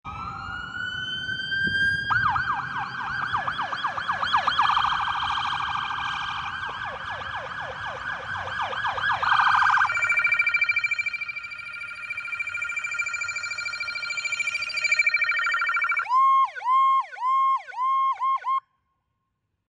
Cobb County PD Dodge Charger Sound Effects Free Download